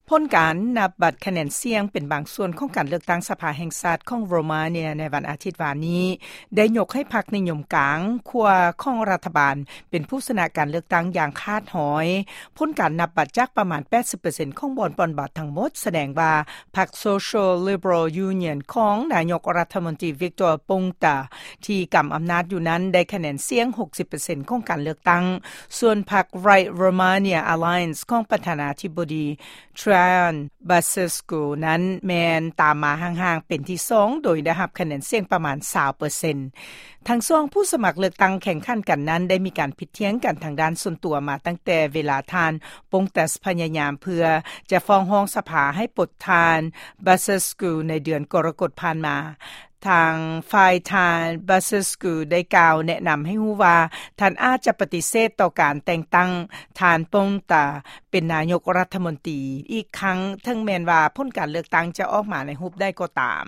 ຟັງຂ່າວ ກ່ຽວກັບໂຣເມເນຍ